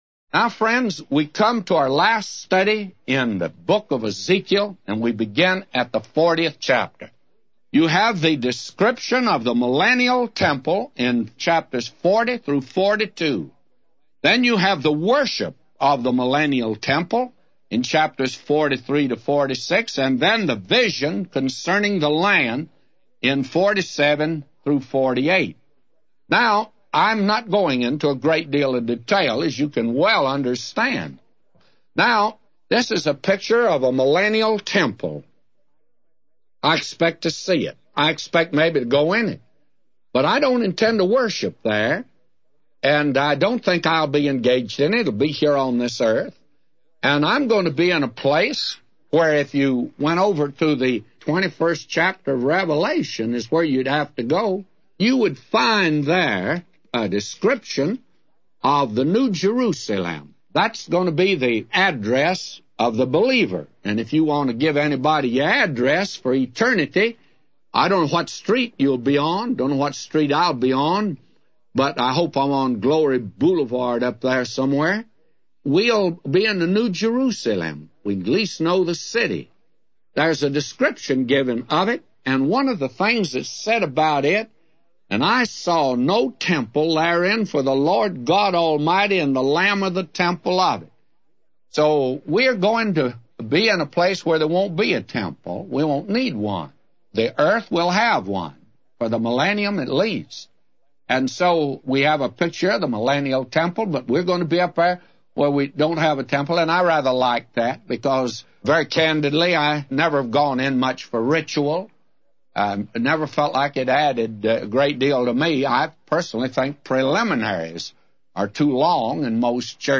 A Commentary By J Vernon MCgee For Ezekiel 40:0-999